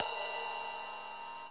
pcp_ride01.wav